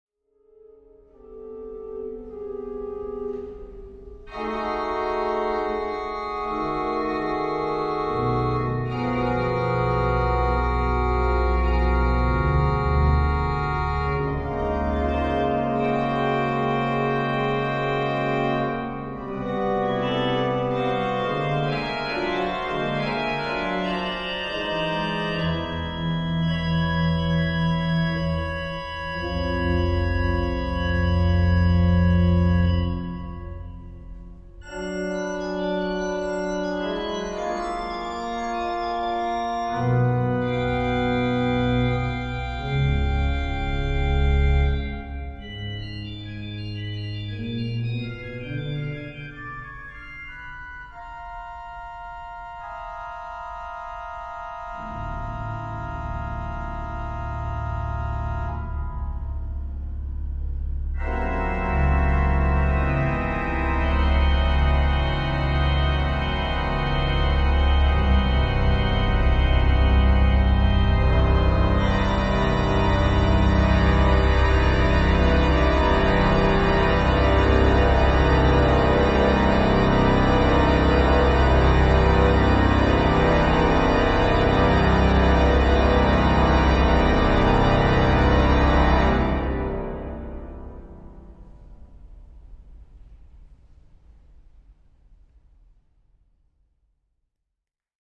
Fragment uit live opname
Catharinakerk Eindhoven,